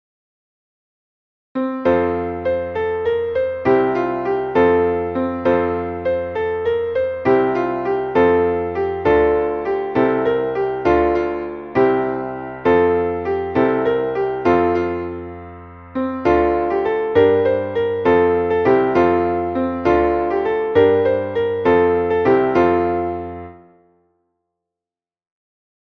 Traditionelles weihnachtliches Wiegenlied